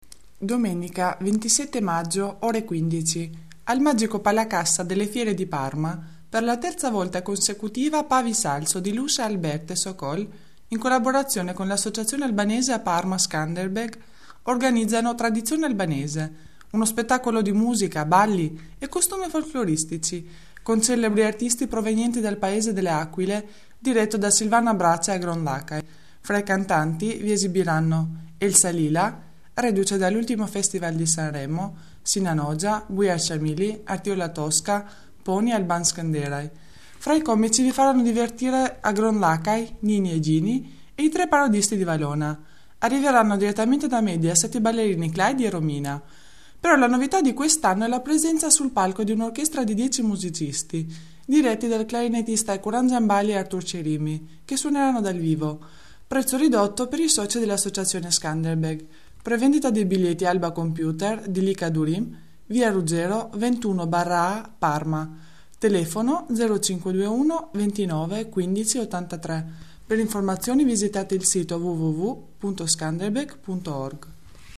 Spot Audio